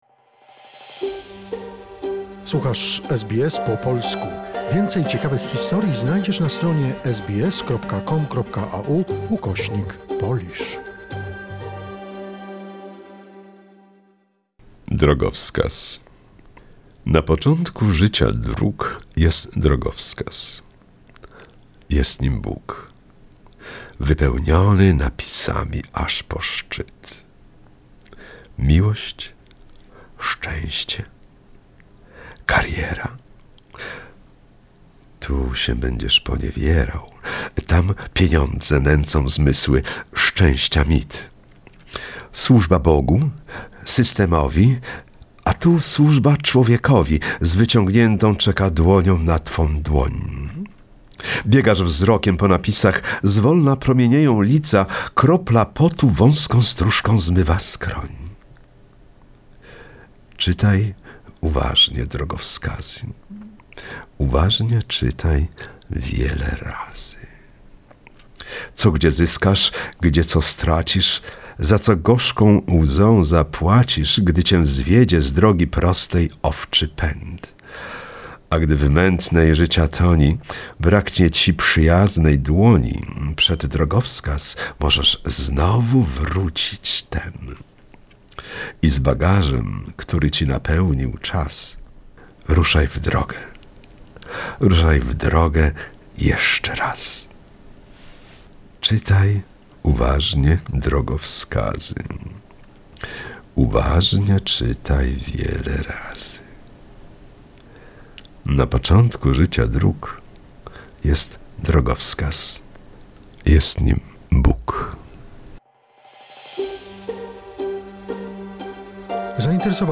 a Sydney poet reads his poem entitled 'Signpost'